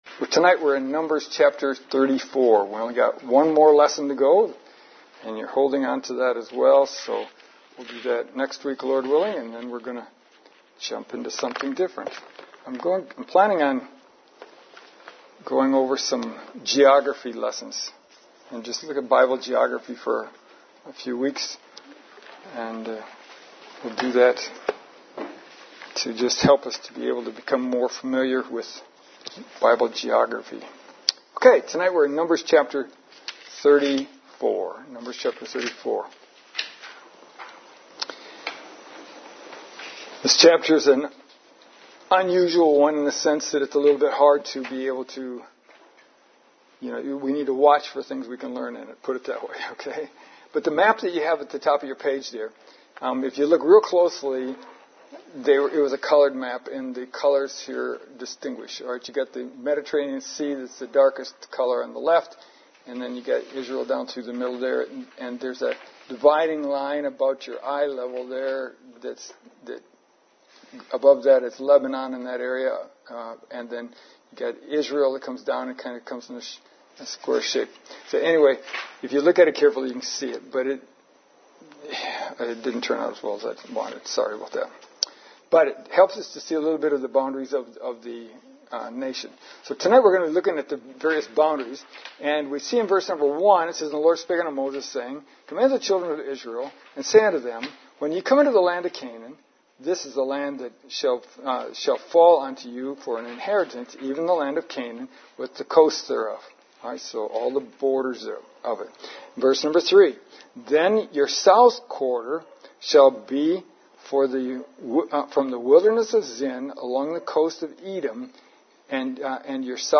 Numbers For our admonition, 1 Corinthians 10:11 3 April 2024 Lesson: 25 Numbers 34 Preparing to Enter the Promised Land Once again, the main outline is from the Open Bible, slightly edited.